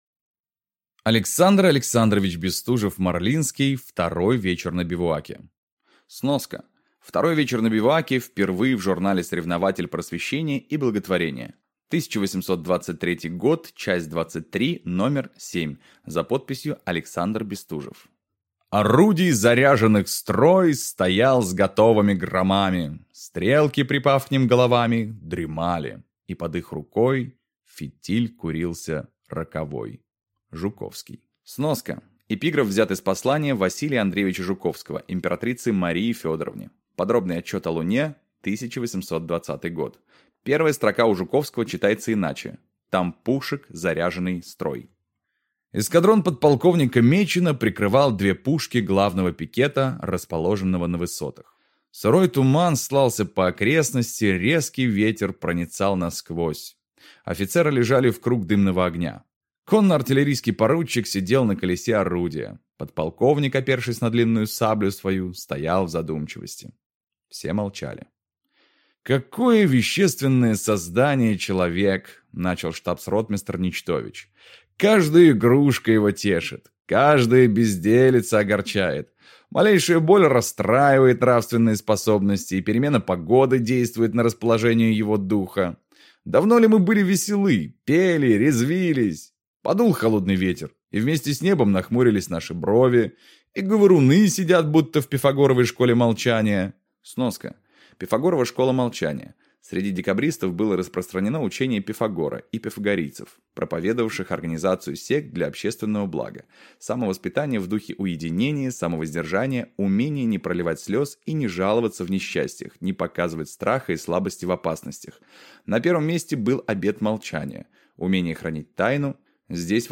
Аудиокнига Второй вечер на бивуаке | Библиотека аудиокниг